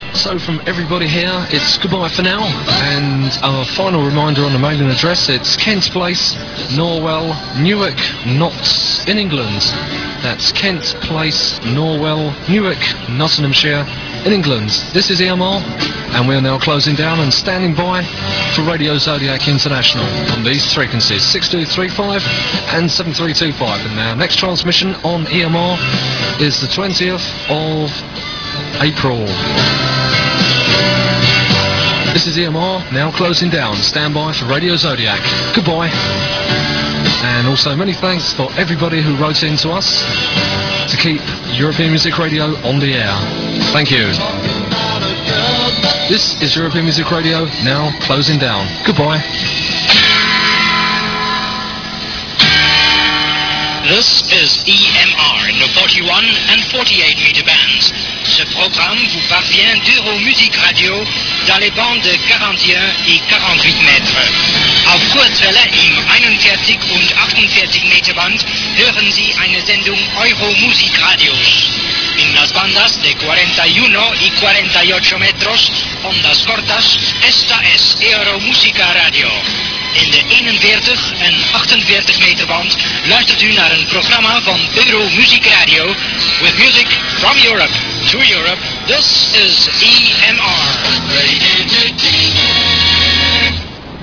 The real highlight for the listening audience occured on the fifth Sunday of a month when the stations would broadcast the famous '6235 kHz Network'. The programmes were often simulcast on 7325khz 41 metres, which would ensure that at least one frequency was clearly audible.
Shortwave broadcasts are difficult to track because after the signal leaves the aerial there is very little ground wave, the signal heading skywards.